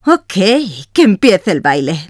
Escucha las voces de algunos de los personajes antes de ser tratadas en post-producción con música y efectos sonoros.